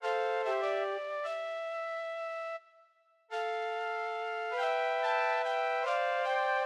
Tag: 130 bpm Acoustic Loops Drum Loops 1.24 MB wav Key : Unknown